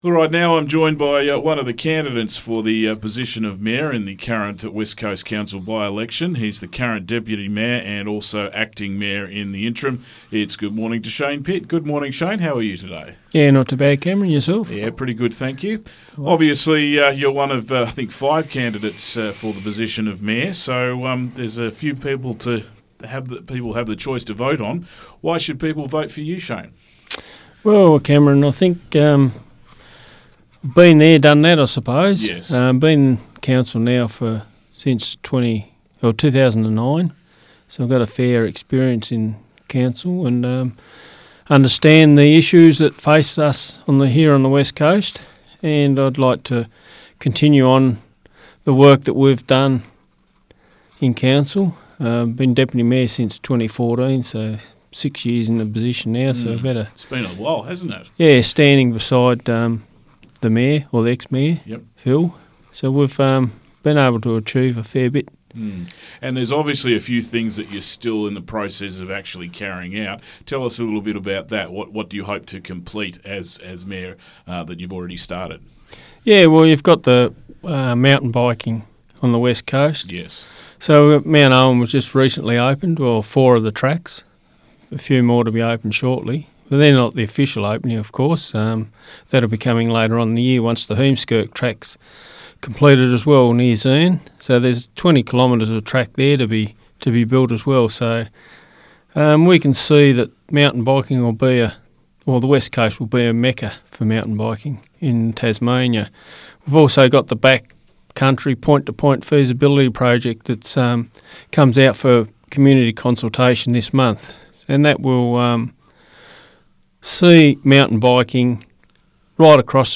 Interview with Shane Pitt